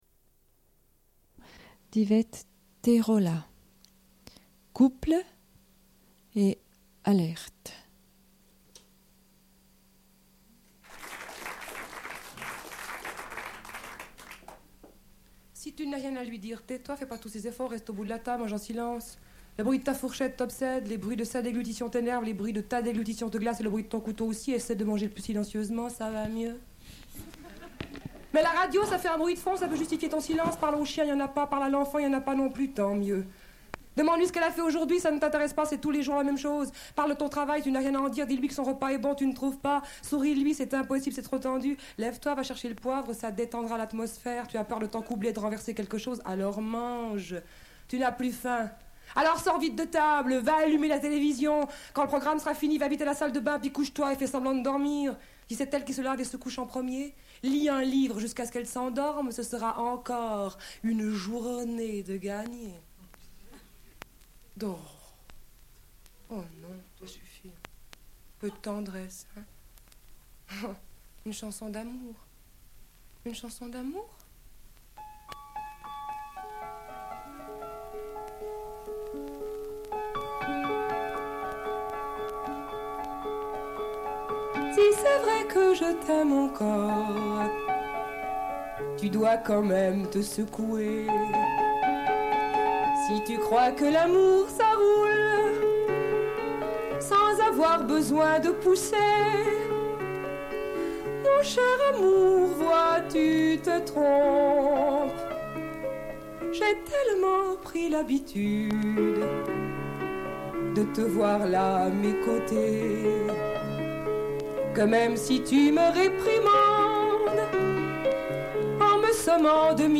00:08:56 // Lecture de la nouvelle Lorsque tout changea de Joanna Russ, publiée dans l'anthologie de Marianne Leconte Femmes au futur (Marabout, 1976).
Une cassette audio.